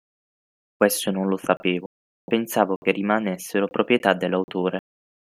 Pronounced as (IPA) /lo/